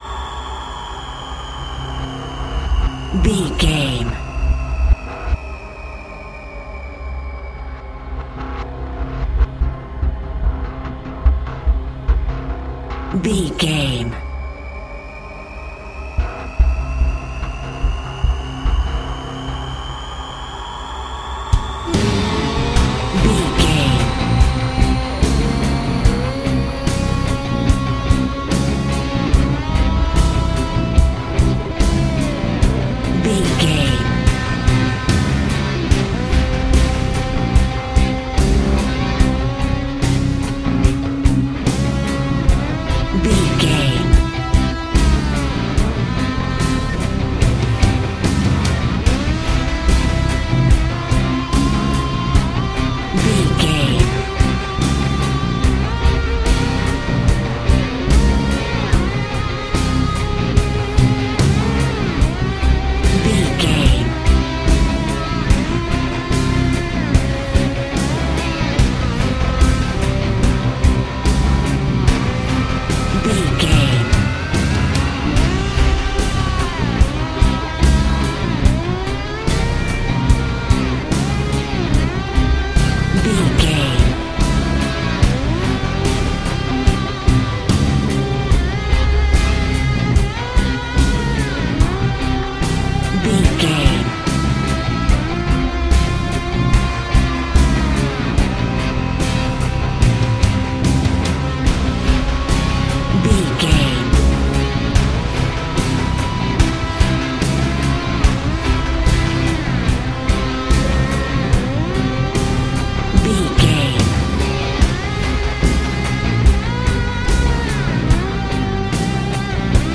Thriller
Aeolian/Minor
synthesiser